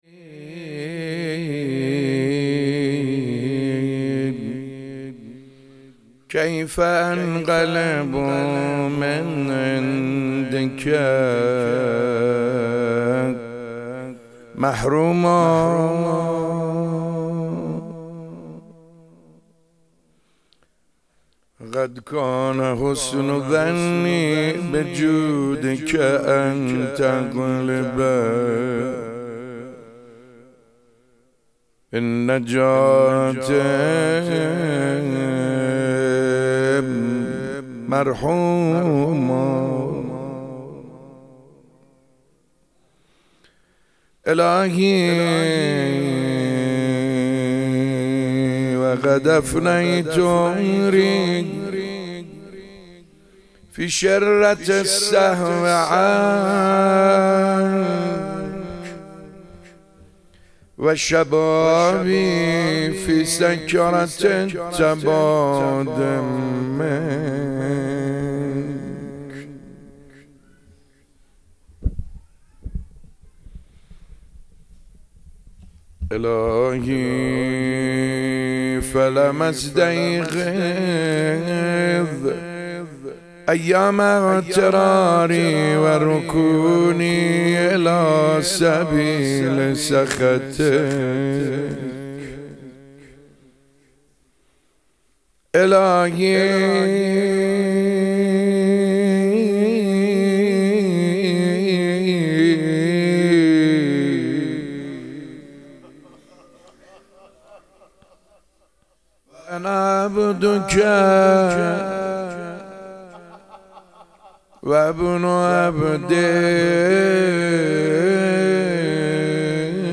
مناجات خوانی